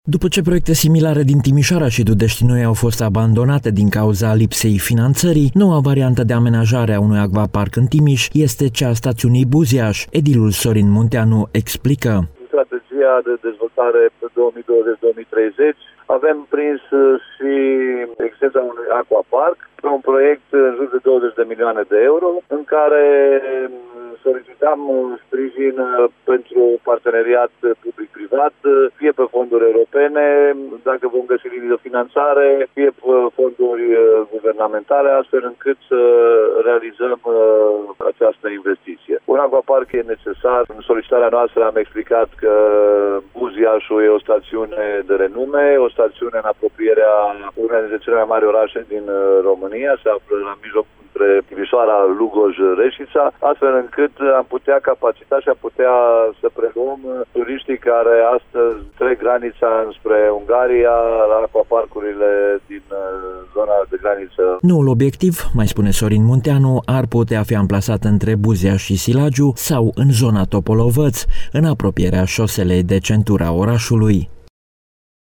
Edilul Sorin Munteanu a declarat pentru Radio Reşiţa că, proiectul în valoare de 20 de milioane de euro avantajează oraşul, aflat la confluenţa judeţelor Timiş şi Caraş-Severin, iar investiţia odată realizată ar putea prelua şi turiştii care se îndreaptă spre zona de graniţă cu Ungaria.